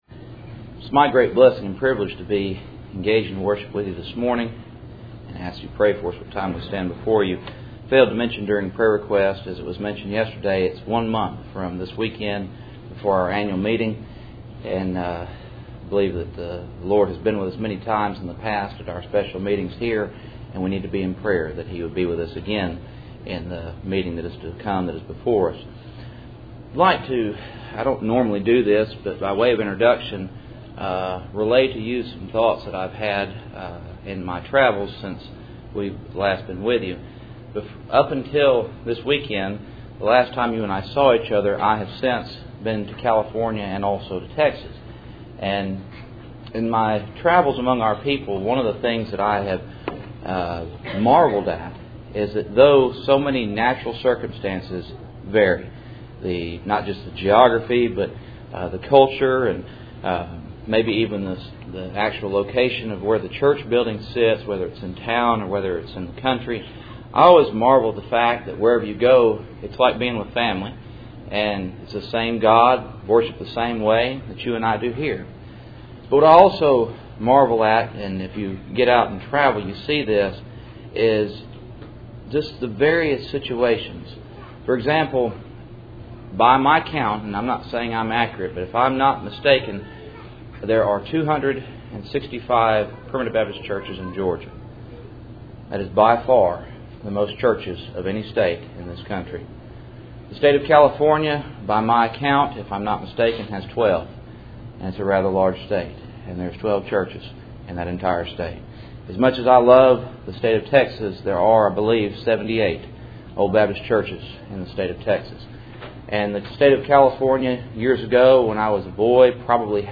Passage: John 1:45-51 Service Type: Cool Springs PBC Sunday Morning %todo_render% « Holiest of All and Old vs New I Peter 5:8-11